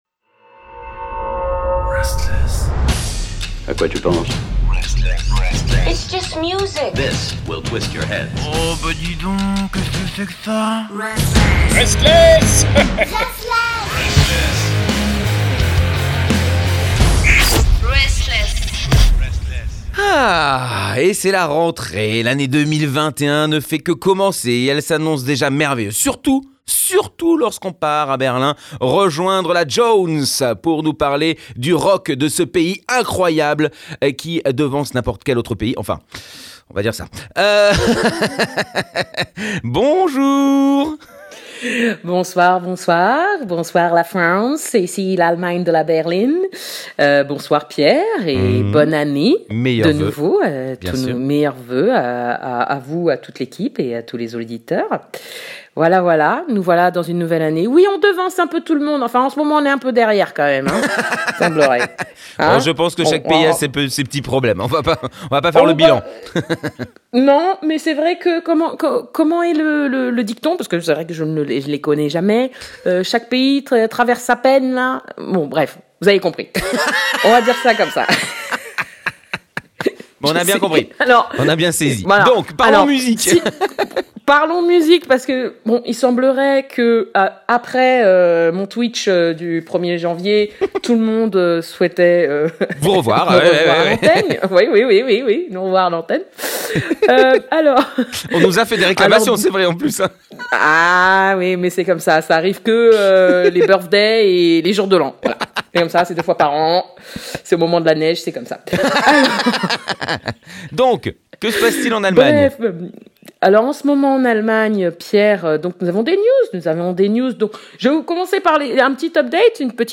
c’est tous les mercredis à 19h sur RSTLSS Radio.